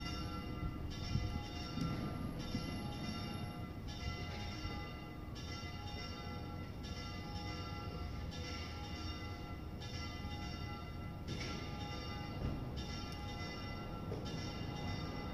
Bonheur, c’est bien une de celles de la cathédrale, qui indique que la messe va bientôt débuter.
L’appel des cloches vers une église, irrésistible…
Ávila-Cloches-cathédrale-1.m4a